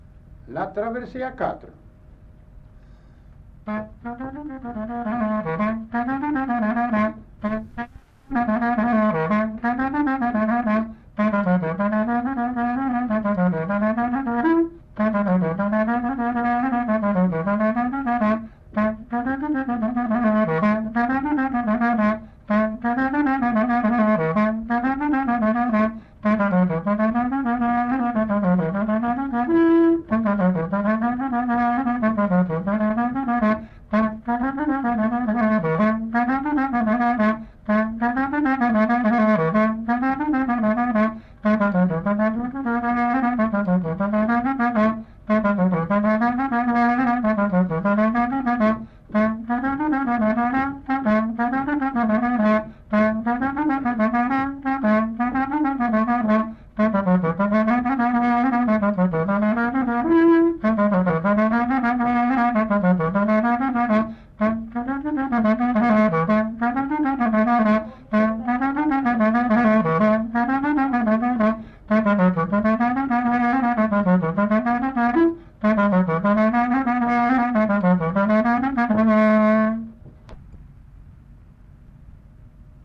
Aire culturelle : Couserans
Genre : morceau instrumental
Instrument de musique : clarinette
Danse : traversée